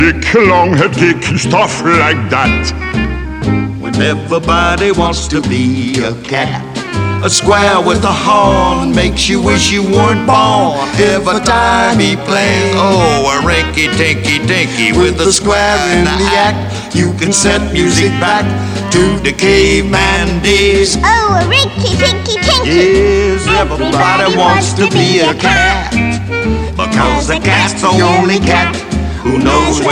Vocal Pop
Жанр: Поп музыка